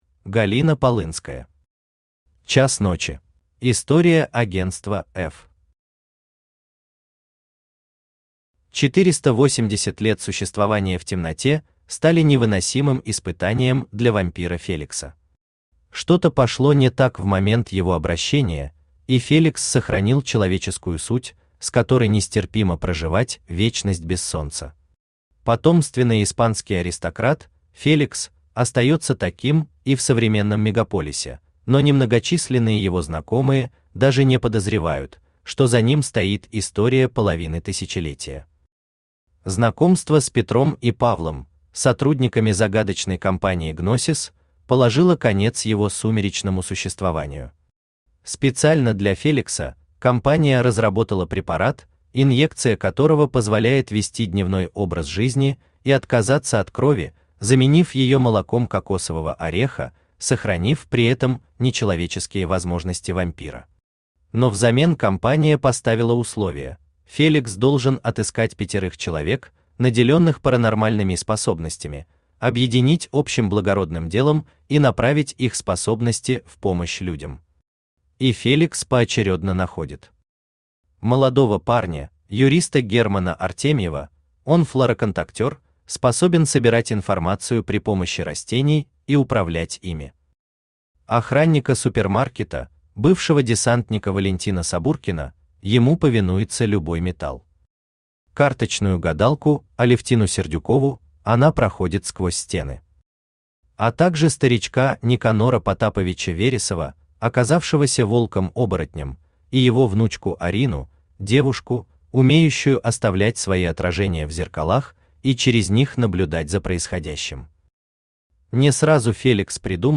Аудиокнига Час ночи | Библиотека аудиокниг